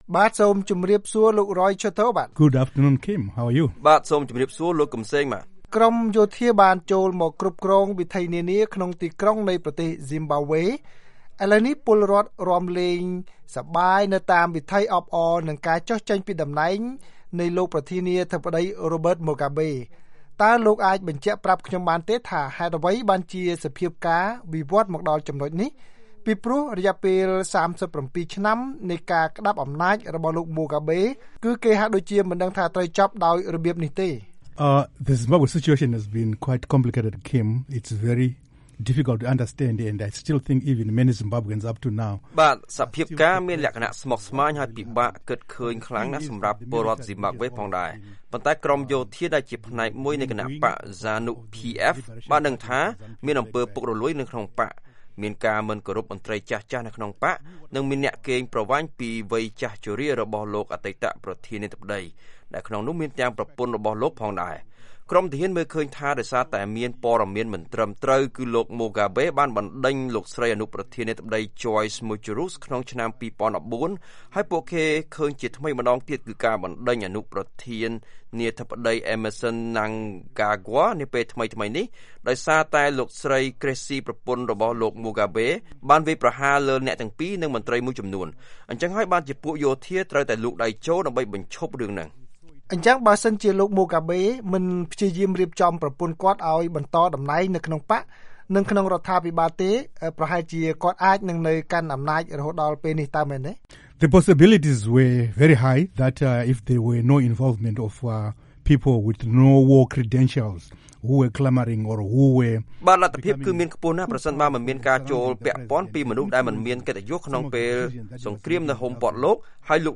បទសម្ភាសន៍VOA៖ ទីបញ្ចប់ដែលមិននឹកស្មានដល់សម្រាប់លោកម៉ូហ្កាបេ